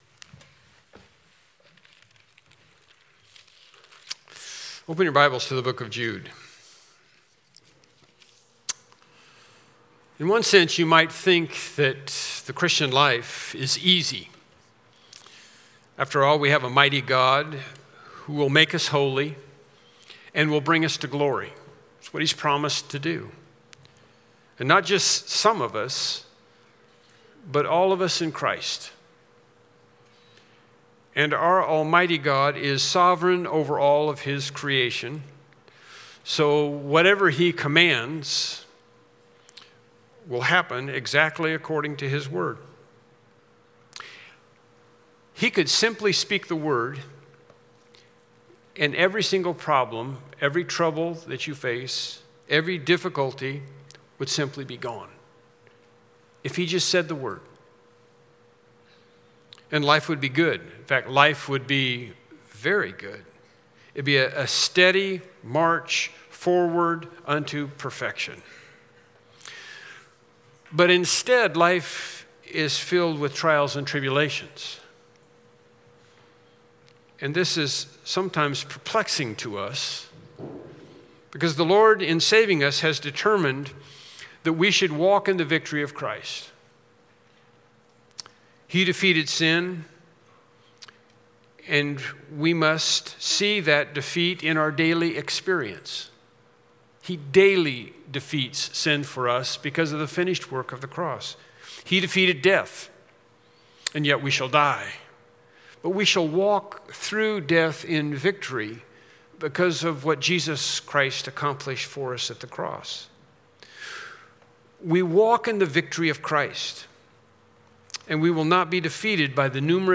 Jude 12-13 Service Type: Morning Worship Service « “Balaam